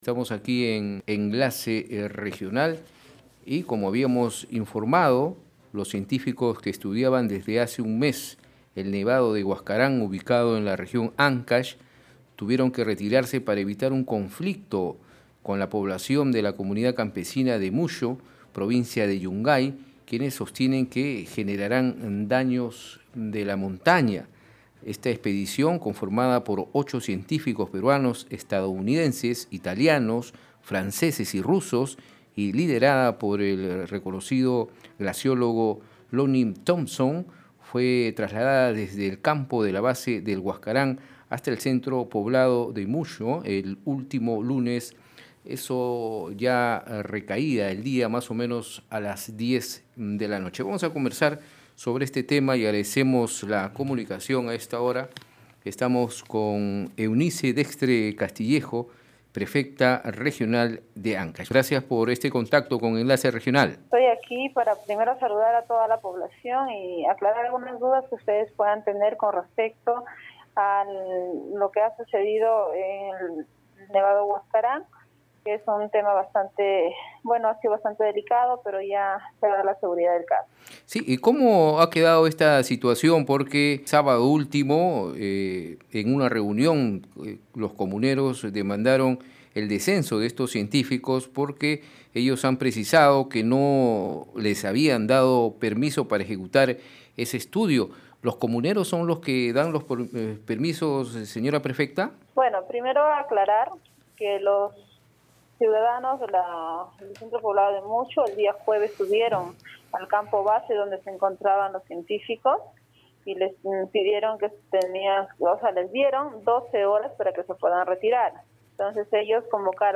En declaraciones a Red de Comunicación Regional, Dextre Castillejo dijo que la población estuvo cegada por información negativa y versiones falsas sobre una supuesta venta del nevado Huascarán para iniciar una extracción minera.